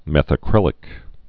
(mĕthə-krĭlĭk)